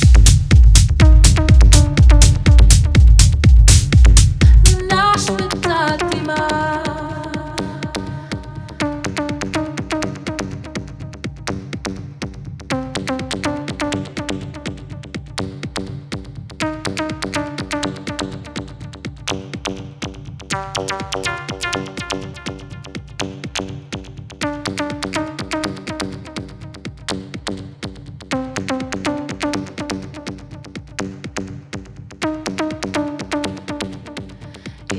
Жанр: Техно